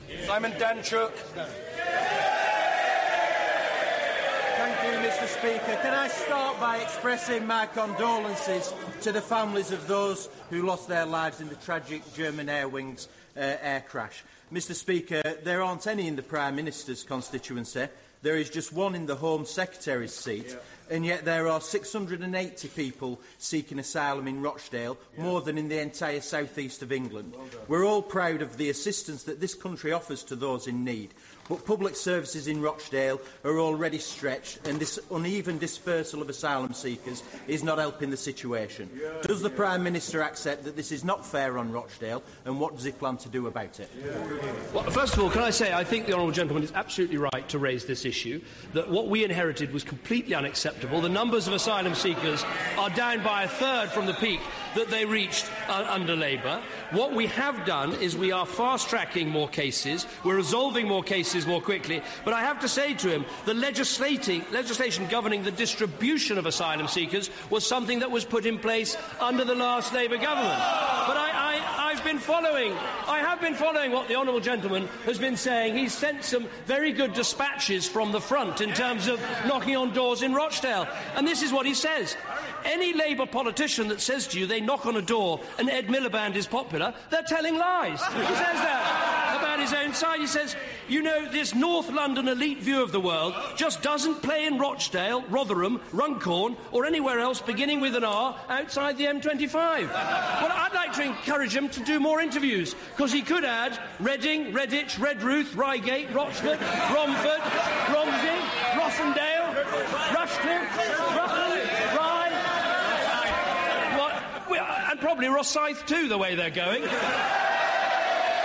David Cameron capitalises on an unhelpful interview that Simon Danczuk had given to the New Statesman when the Labour MP asked a question at PMQs today. 25 March 2015.